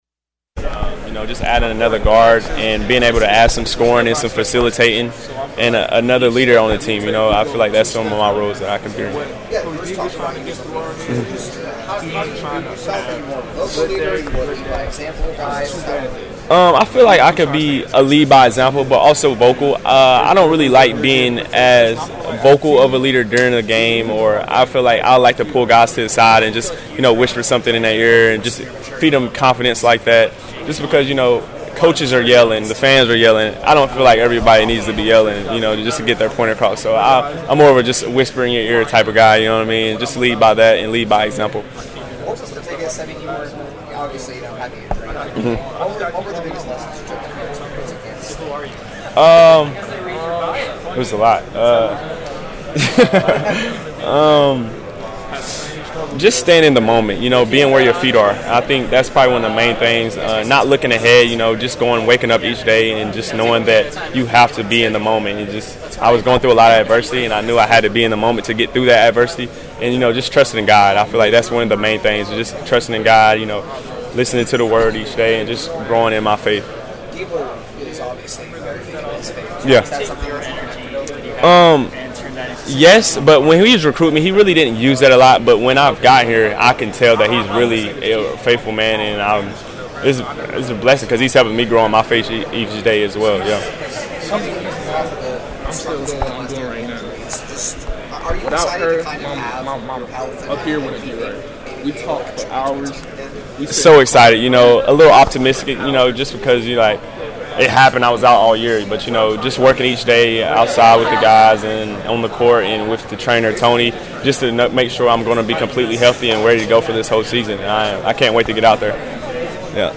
talks during 2024-25 Media Day